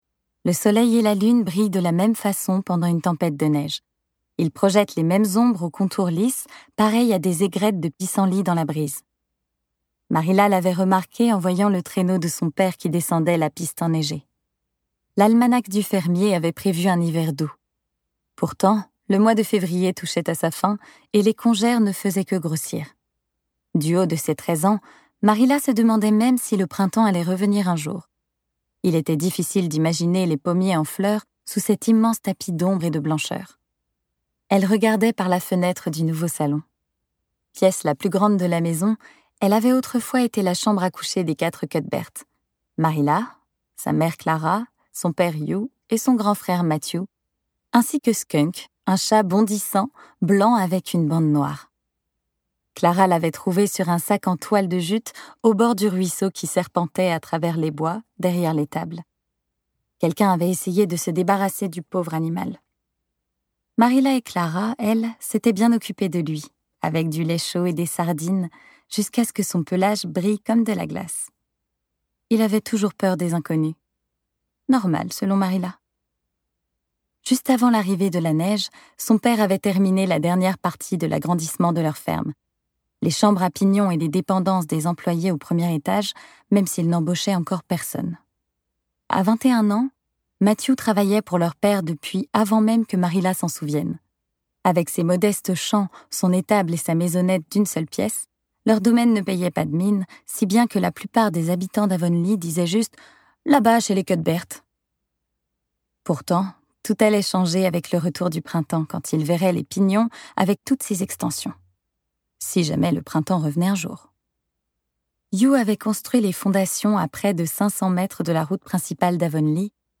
Extrait gratuit - Le Bruissement du papier et des désirs de Sarah McCOY